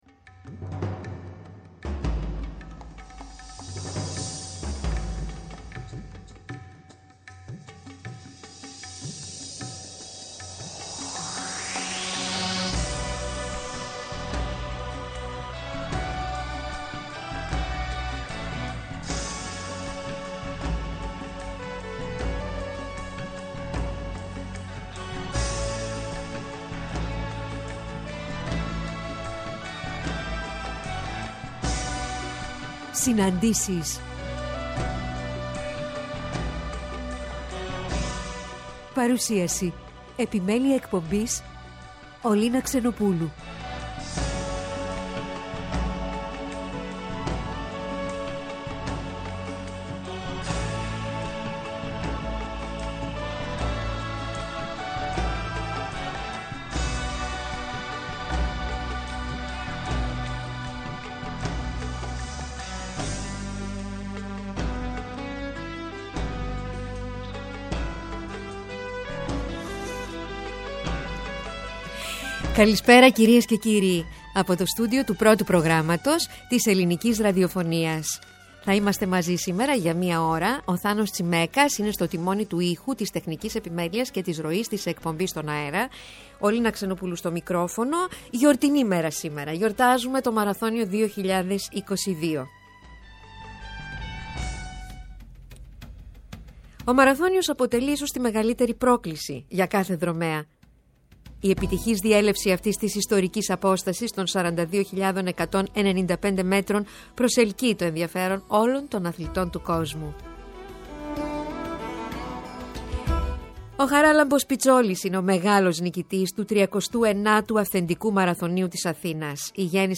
Στις «Συναντήσεις» του Πρώτου Προγράμματος την Κυριακή 13-11-22 ώρα 16:00-17:00 καλεσμένοι: